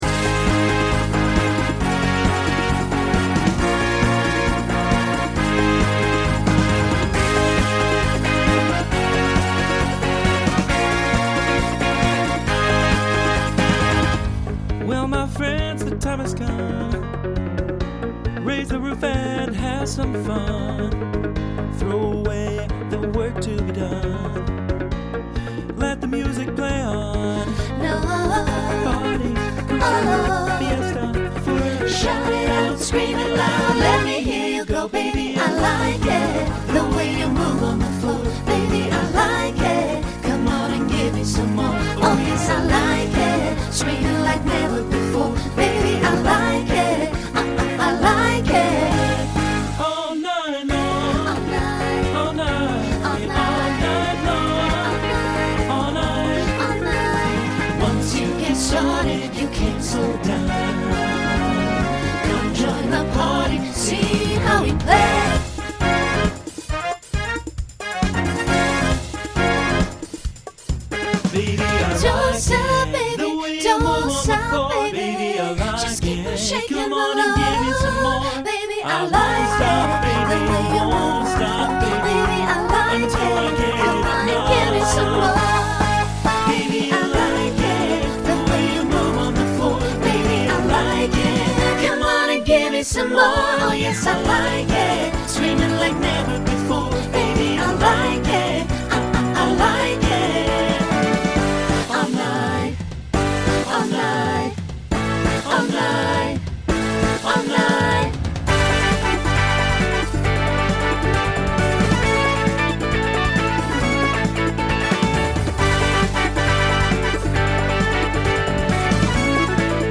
SATB Instrumental combo
Pop/Dance